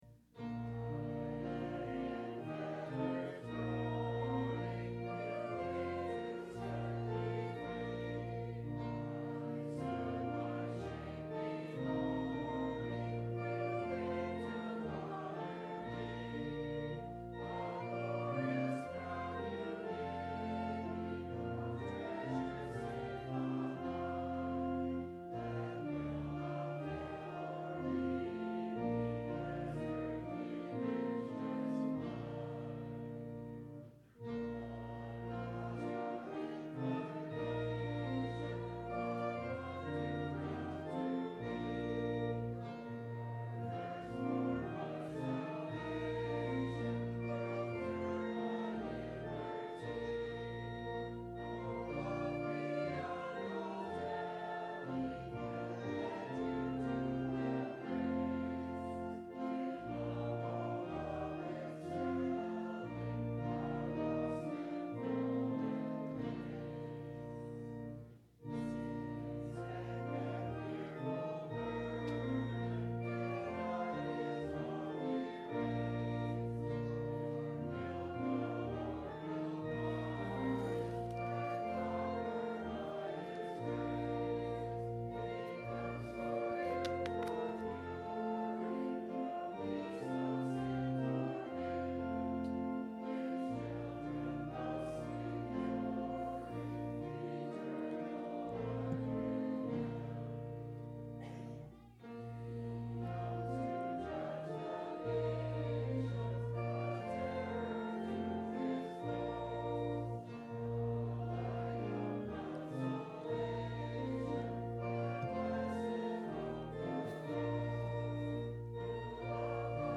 What Does It Mean to Be Justified ? – Sermon – December 12 2010